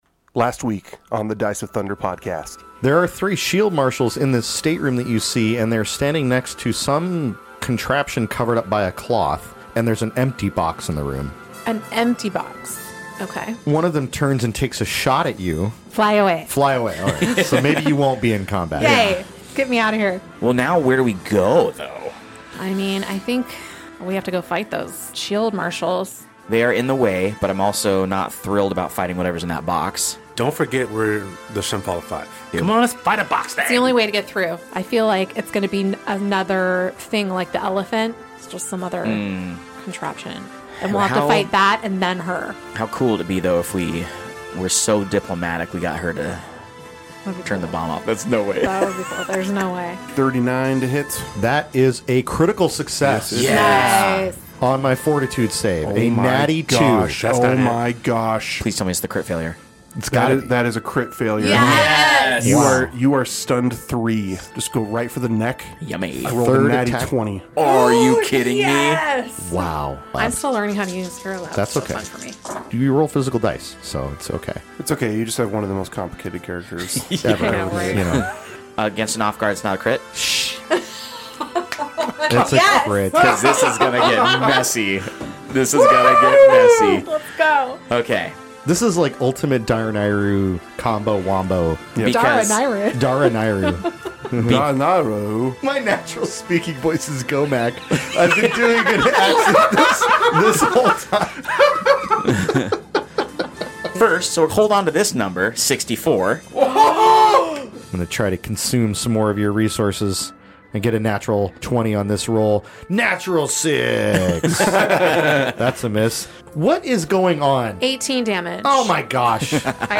A Pathfinder 2nd Edition actual play podcast suitable for all ages! Join us as we play through the Outlaws of Alkenstar adventure path published by Paizo Inc. as part of the Pathfinder 2nd Edition tabletop role-playing game.
The show consists of new players and an experienced GM.